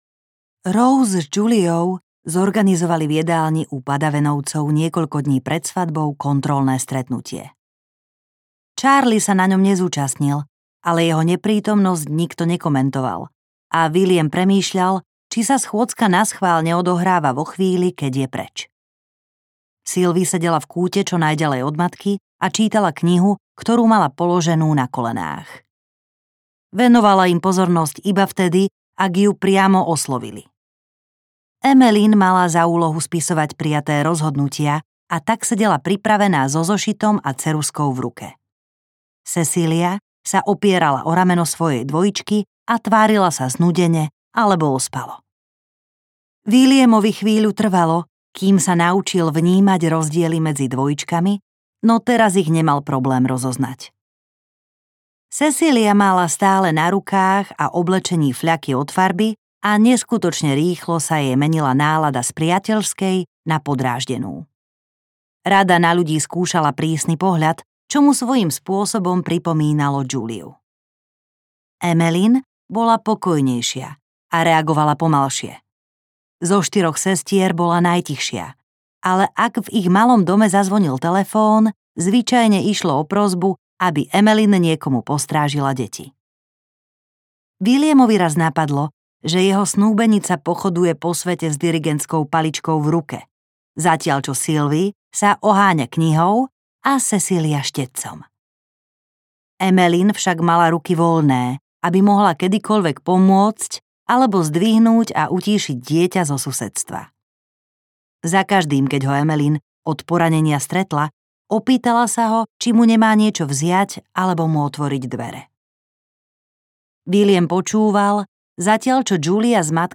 Ahoj, dievčatko audiokniha
Ukázka z knihy